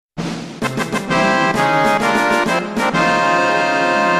Play, download and share Fanfara Vittoria (2) original sound button!!!!